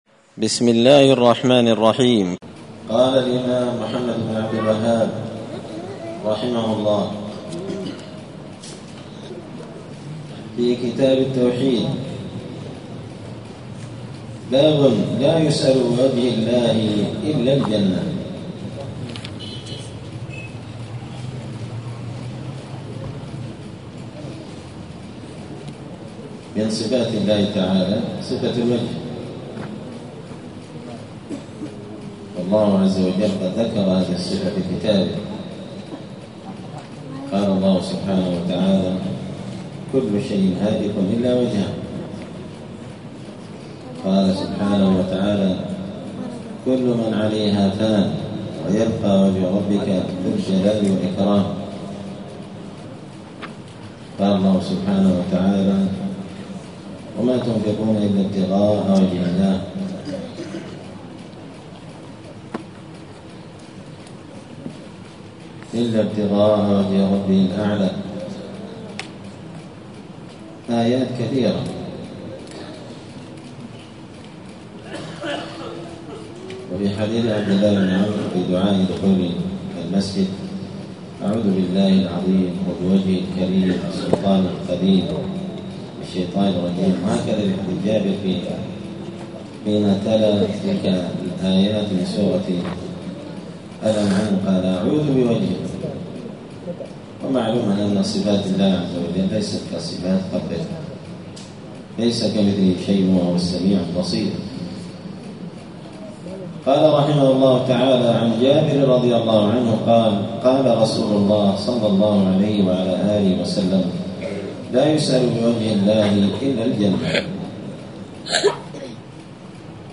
دار الحديث السلفية بمسجد الفرقان قشن المهرة اليمن
*الدرس السادس والثلاثون بعد المائة (136) {باب لا يسأل بوجه الله إلا الجنة}*